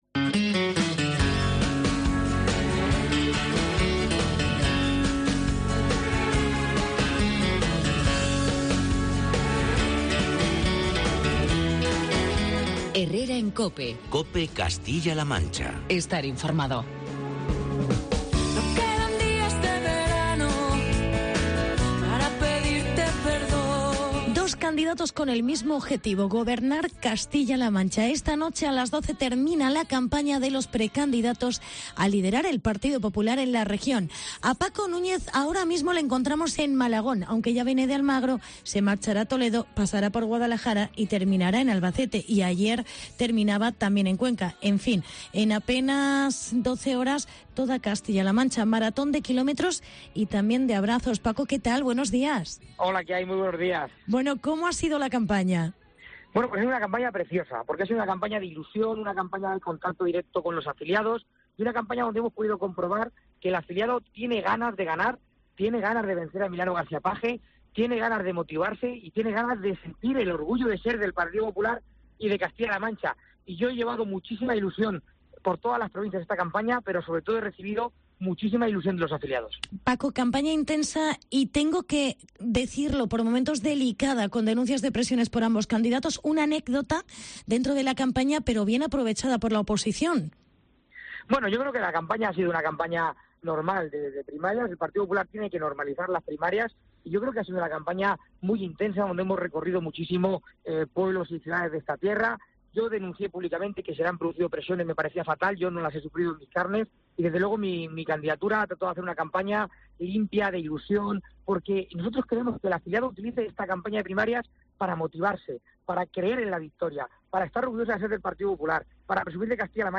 Entrevista con Paco Núñez. Precandidato PP CLM